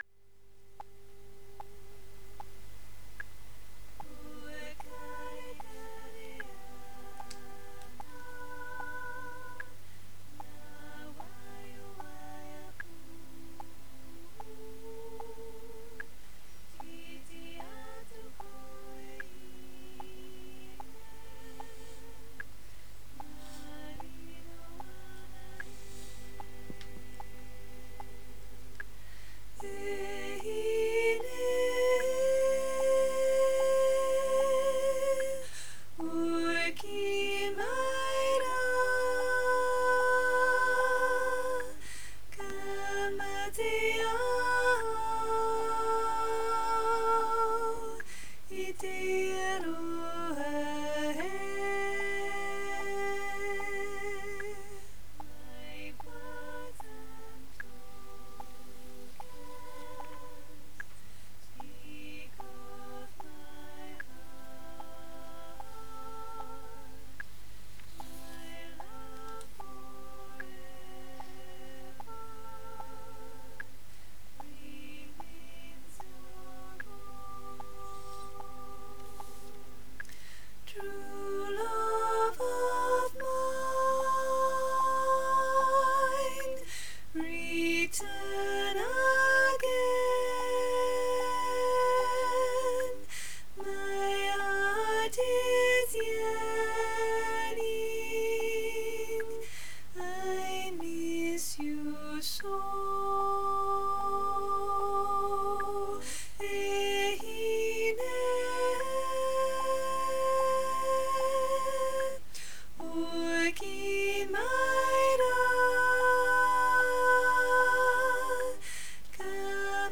Pokarekare Ana SOP